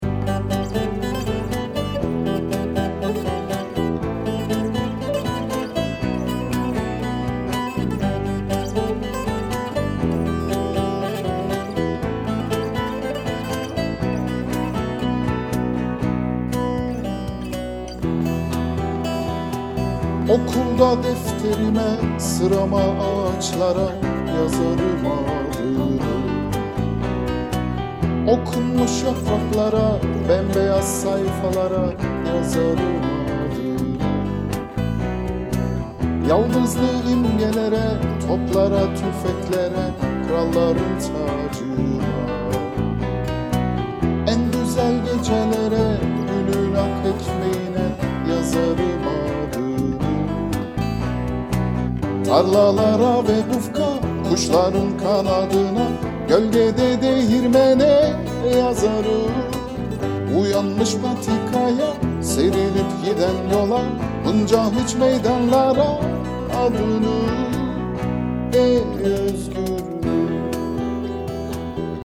Türkisches Freiheitslied
Wir notieren die Noten, nehmen die Lieder auf und Muttersprachler:innen singen die Melodie ein.